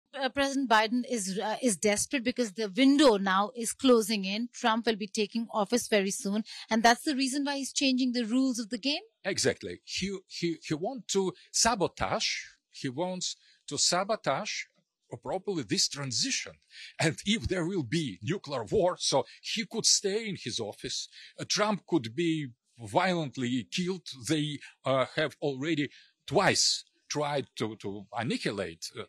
In this revealing interview, Aleksandr Dugin discusses what he sees as President Joe Biden's desperate efforts to block Donald Trump's return to power. Dugin, a key Russian political philosopher, argues that Biden's actions are driven by a fear of Trump's resurgence in U.S. politics. He explores how this political battle could impact the global stage, with the potential to shift the balance of power between the U.S. and other nations.